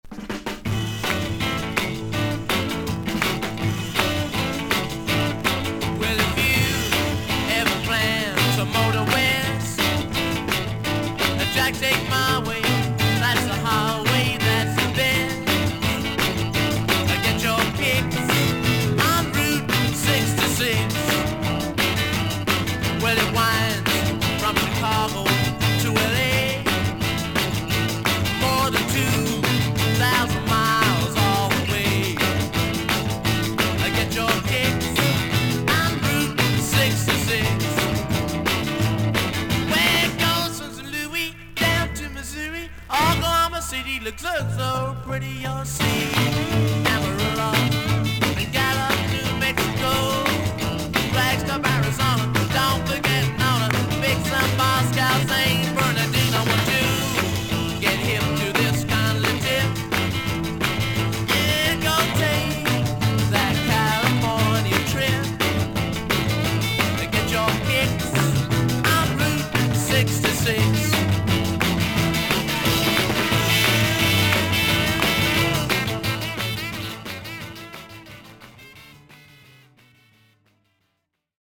少々サーフィス・ノイズはありますがパチノイズの箇所はありませんでした。クリアな音です。